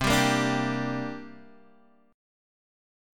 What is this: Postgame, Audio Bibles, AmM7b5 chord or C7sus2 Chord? C7sus2 Chord